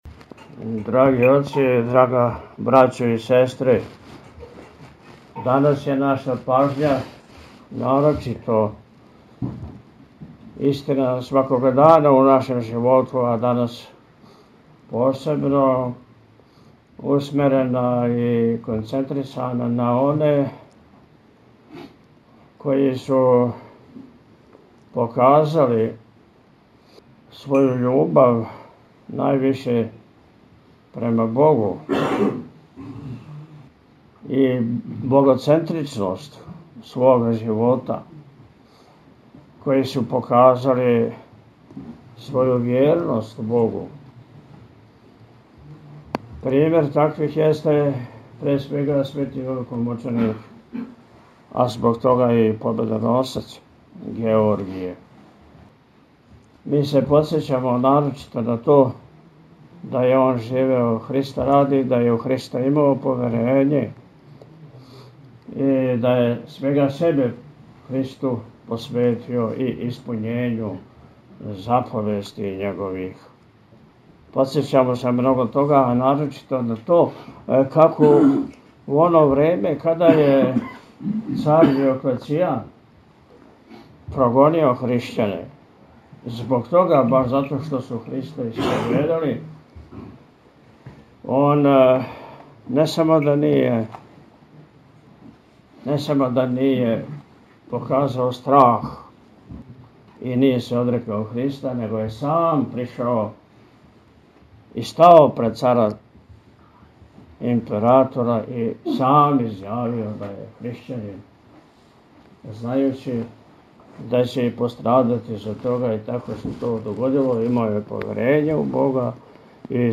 Жанр: Blues.